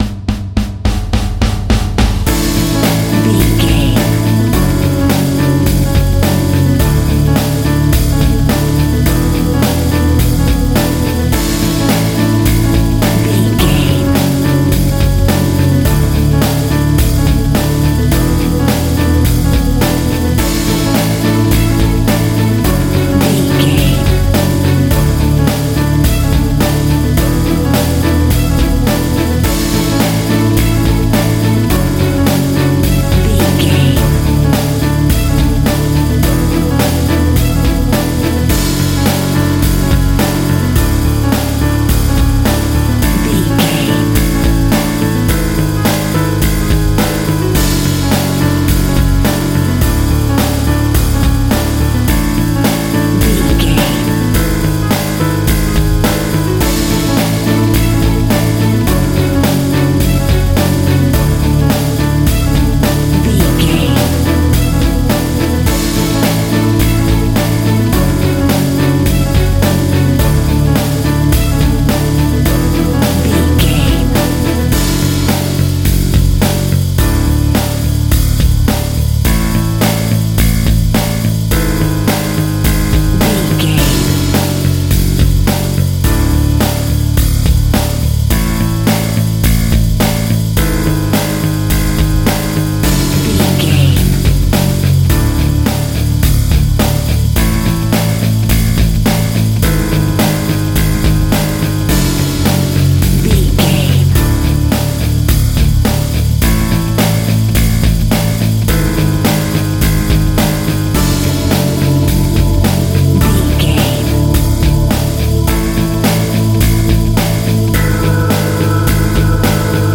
Piano Rock Music.
Ionian/Major
D
indie pop
fun
energetic
uplifting
drums
bass guitar
hammond organ
synth